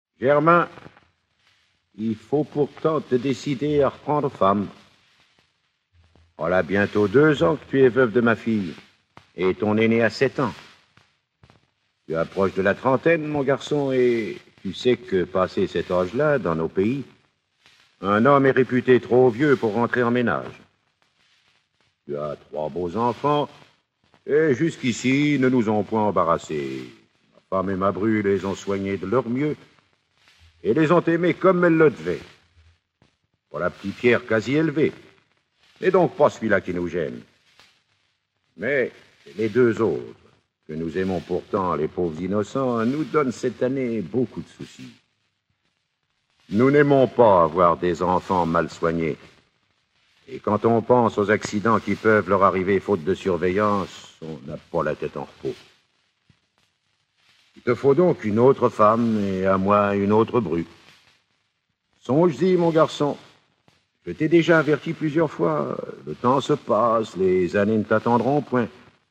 Diffusion distribution ebook et livre audio - Catalogue livres numériques
Enregistrement original (extraits)